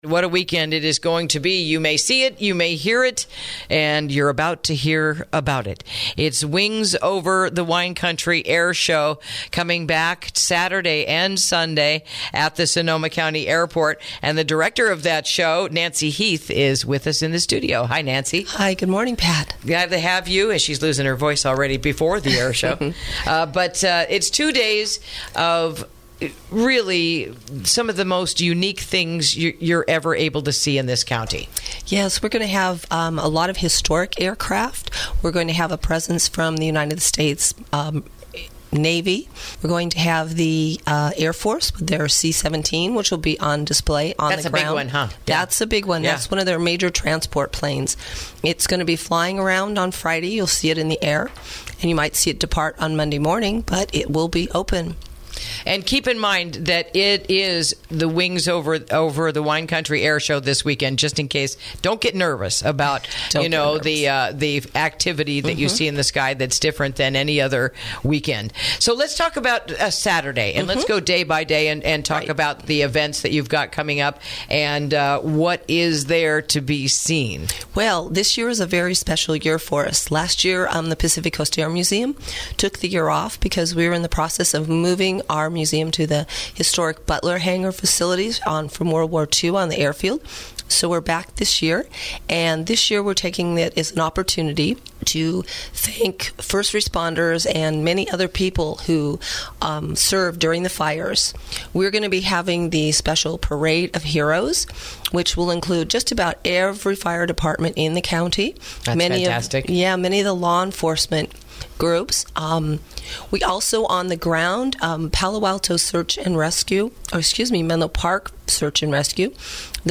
INTERVIEW: Wings Over Wine Country This Weekend at the Sonoma County Airport